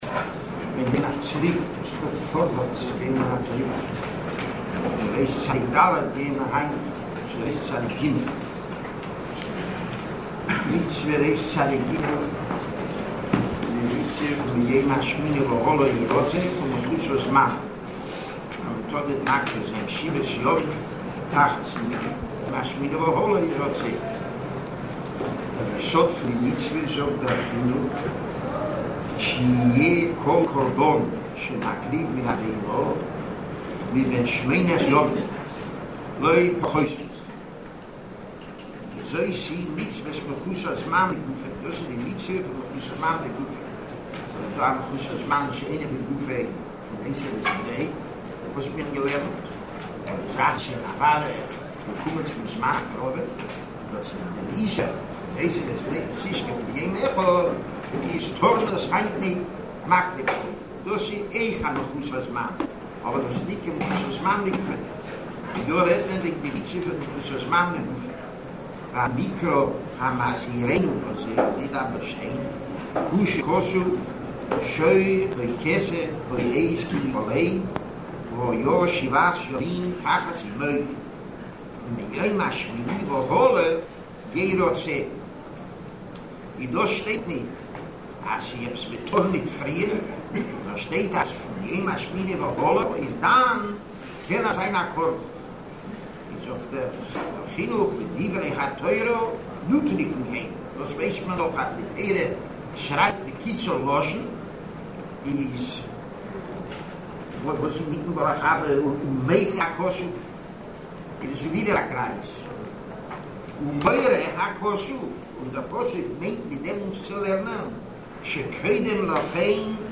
Rav Gifter giving shiur on Minchas Chinuch – Mitzvah 293, A Koran has to be from eight days and on.